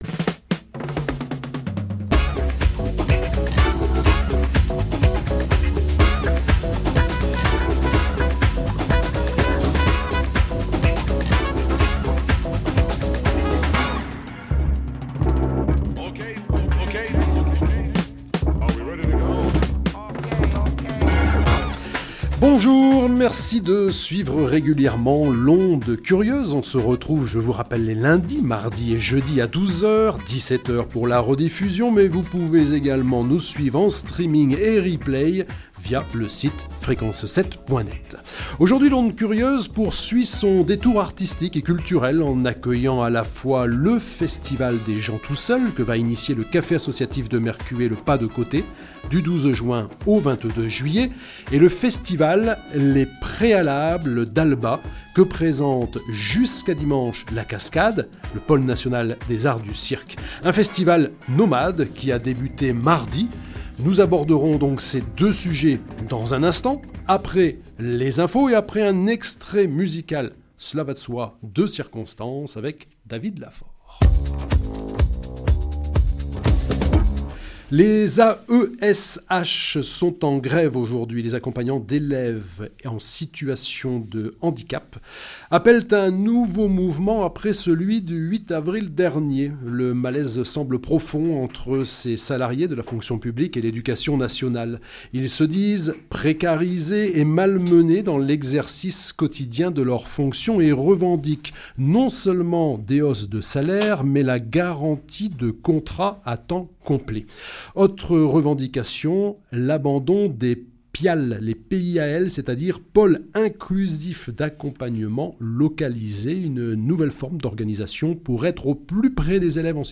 Interview du 3 juin 2021 sur Fréquence 7 (Onde Curieuse)